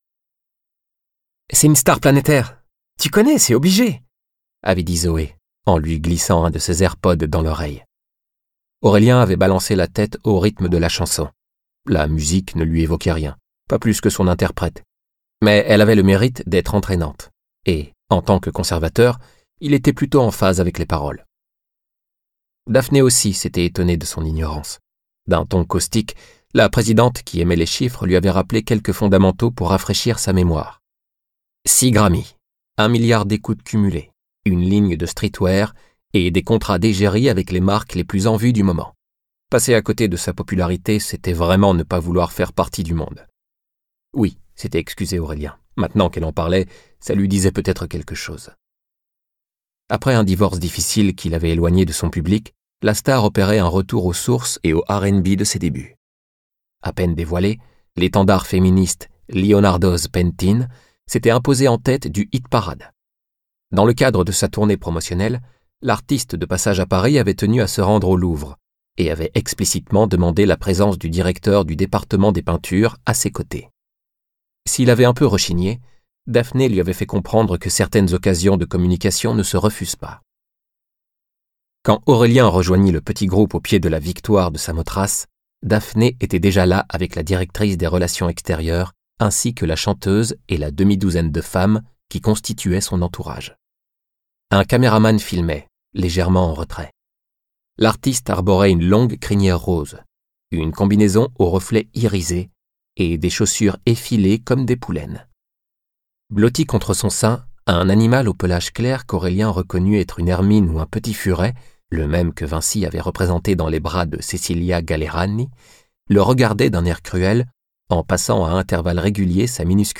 Click for an excerpt - L'allègement des vernis de Paul Saint Bris